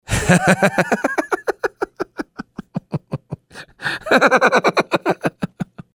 Play, download and share KM – Kevin laugh 1 original sound button!!!!
kevin-laugh-track-1.mp3